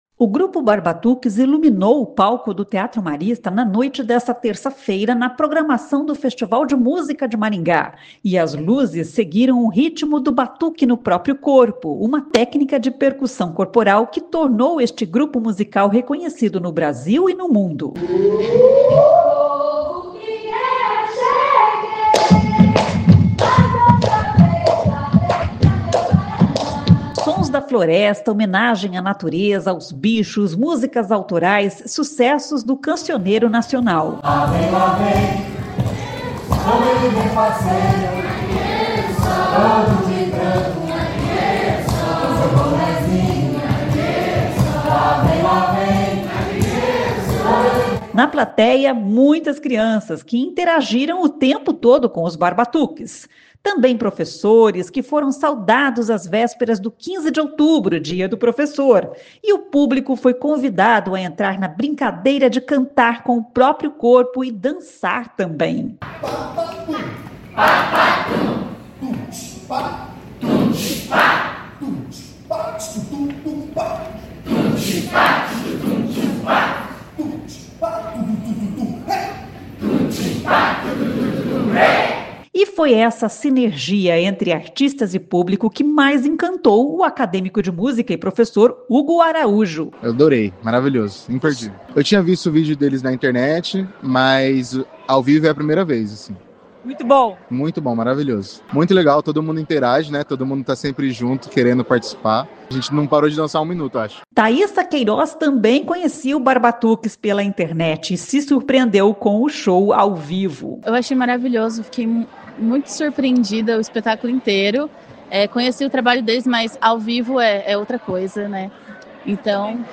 A música do grupo de percussão corporal contagiou o público que entrou na  brincadeira e também “cantou” com o corpo.
O Grupo Barbatuques iluminou o palco do Teatro Marista na noite dessa terça-feira (14) na programação do Festival de Música de Maringá.
E as luzes seguiram o ritmo do batuque no próprio corpo, uma técnica de percussão corporal que tornou este grupo musical reconhecido no Brasil e no mundo.
Sons da floresta, homenagem à natureza, aos bichos, músicas autorais, sucessos do cancioneiro nacional.
Na plateia, muitas crianças, que interagiram o tempo todo com os Barbatuques.